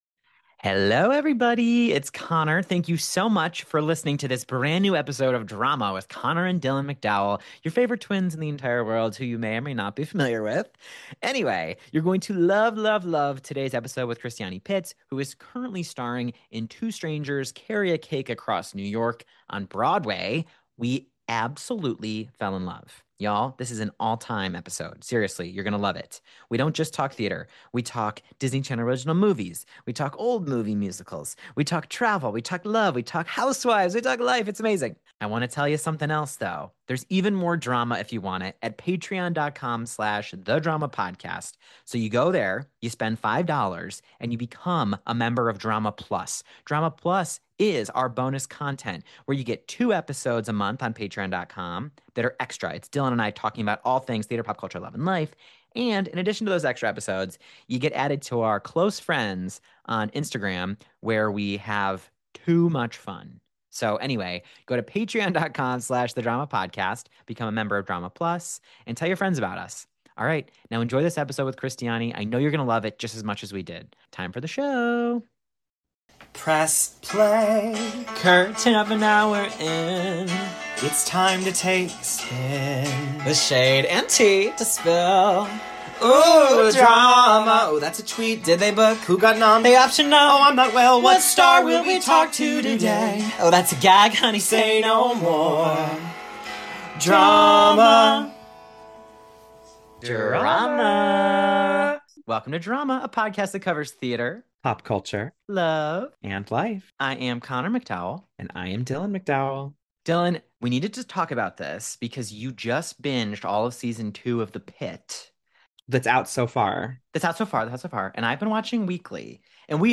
Three strangers (two of which are twins…) became SISTERS during this all-time dynamic, breezy, and gorgeous convo. Hear all about starring as Robin in the hit new musical Two Strangers, from first listen to audition to Boston to BROADWAY.